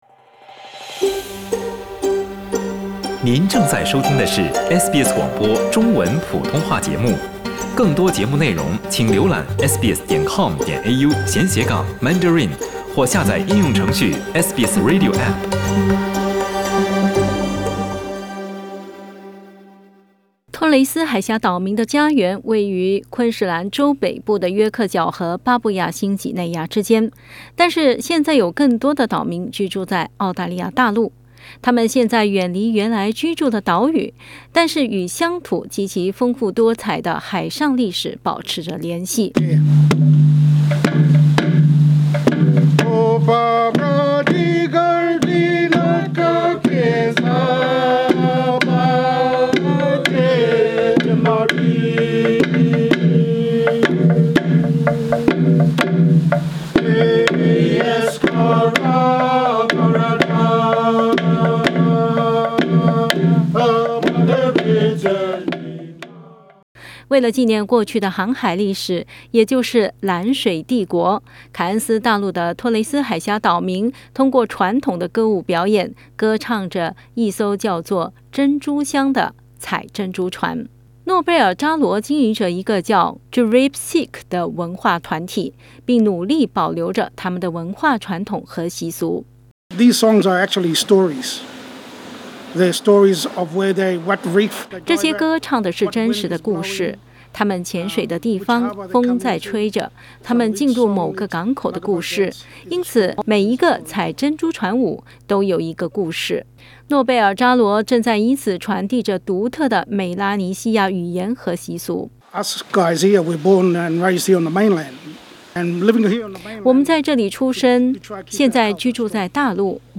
托雷斯海峡岛民曾经以采摘珍珠为生。时至今日，他们的后代通过采珍珠船舞来继承祖先们的传统文化和习俗。 点击图片收听详细报道。